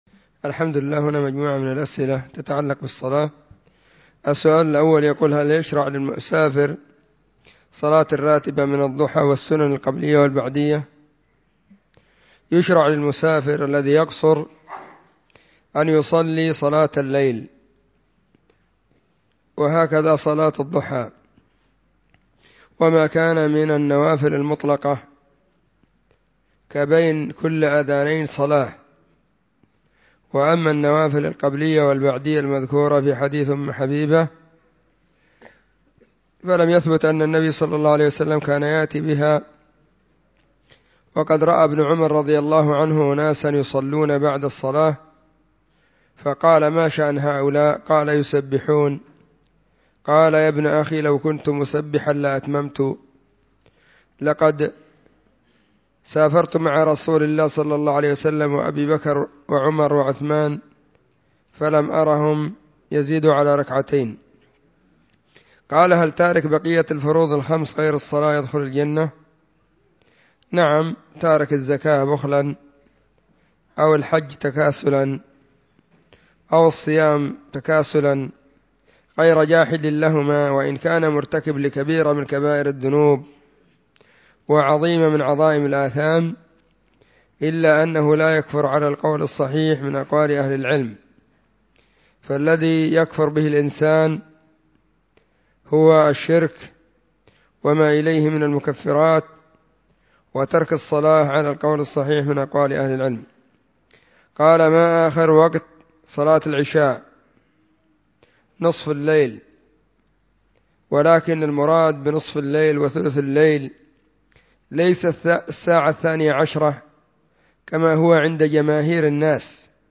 📢مسجد – الصحابة – بالغيضة – المهرة، اليمن حرسها الله.